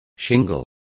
Also find out how guijo is pronounced correctly.